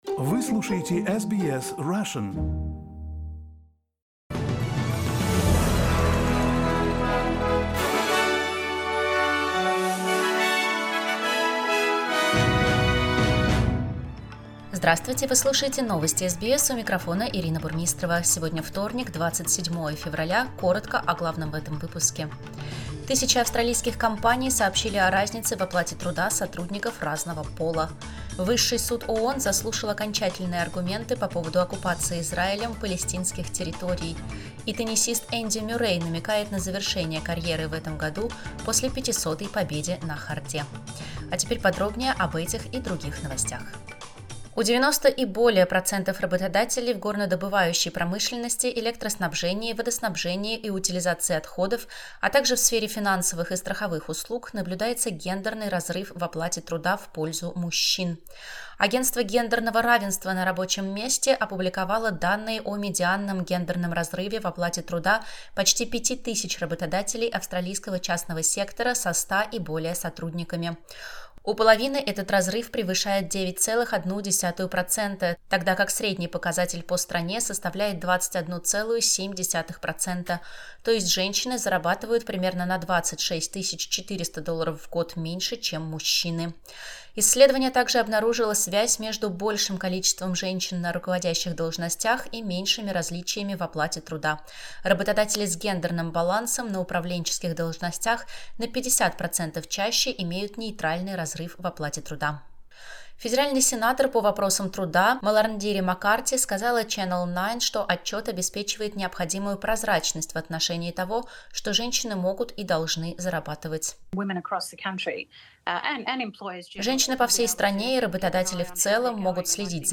SBS news in Russian — 27.02.2024